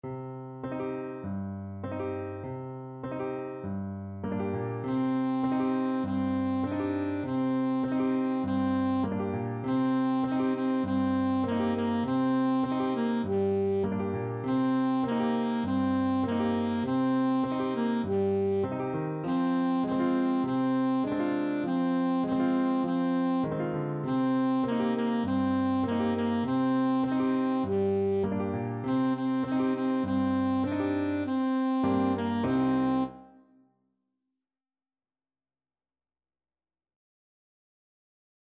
Alto Saxophone
C minor (Sounding Pitch) A minor (Alto Saxophone in Eb) (View more C minor Music for Saxophone )
Moderato
Traditional (View more Traditional Saxophone Music)